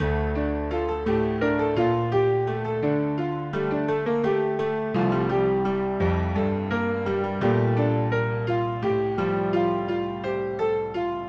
标签： 85 bpm Chill Out Loops Piano Loops 1.90 MB wav Key : E
声道立体声